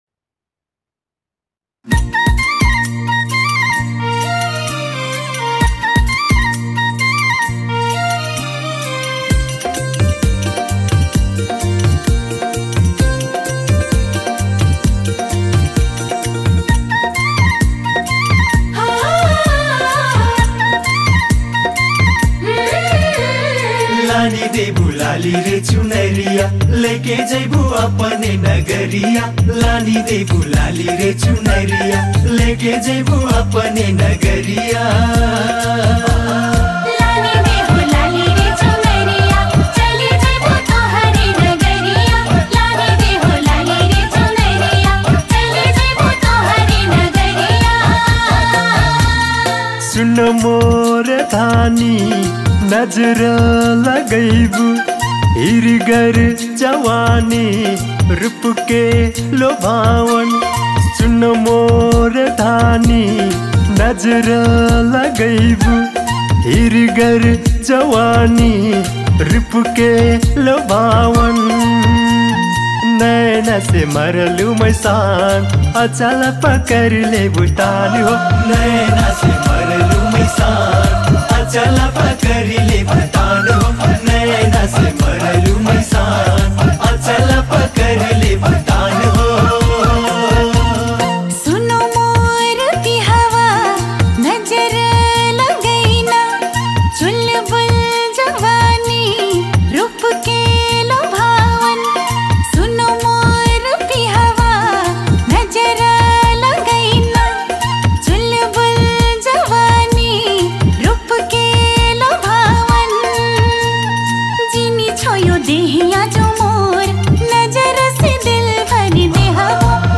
New Tharu Song 2025 Tharu Culture Song